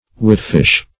witfish - definition of witfish - synonyms, pronunciation, spelling from Free Dictionary Search Result for " witfish" : The Collaborative International Dictionary of English v.0.48: Witfish \Wit"fish`\, n. (Zool.)
witfish.mp3